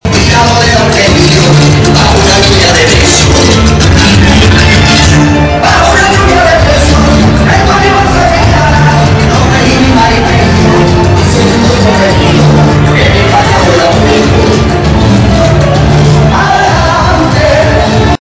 Sevillanas + chotis = feria de Gijón
7247-sevillanas-chotis-feria-de-gijon.mp3